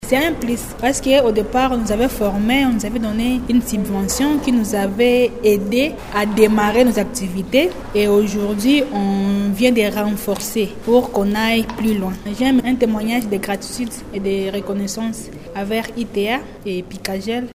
La cérémonie de leur décoration a été organisée le vendredi 24 mai 2024 dans la salle de conférence de l’Ecole Enfant Internationale du Monde situé sur avenue Résidence dans la commune d’IBANDA.
Elle exprime ici sa joie.